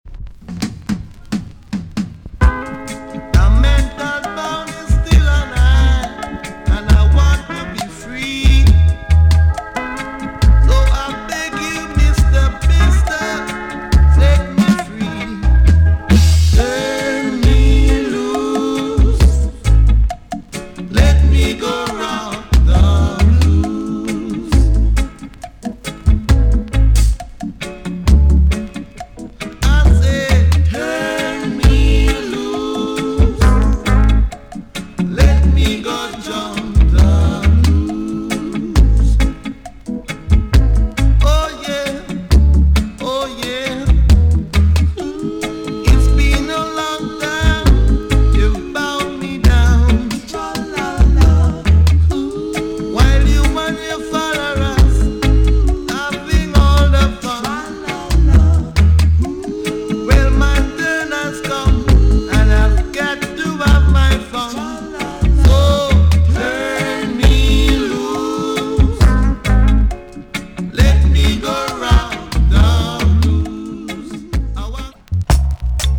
TOP >REGGAE & ROOTS
EX- 音はキレイです。
UK , NICE ROOTS VOCAL TUNE!!